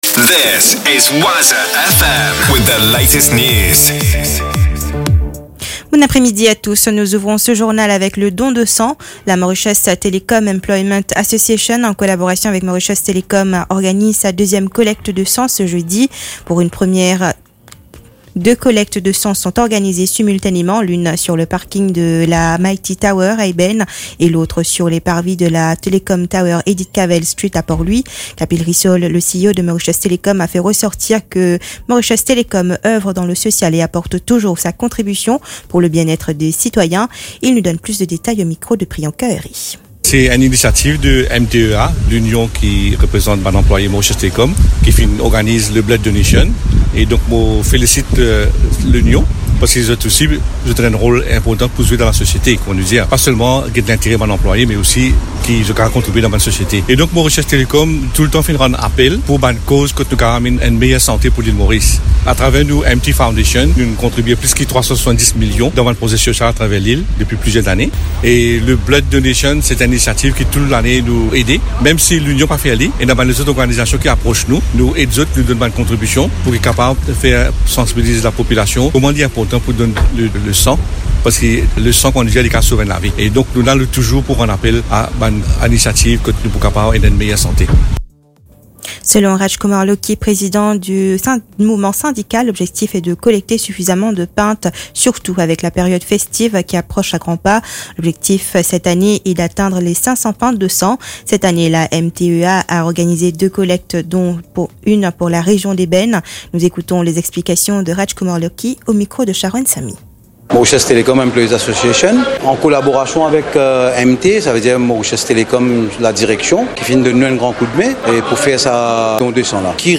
NEWS 16H - 23.11.23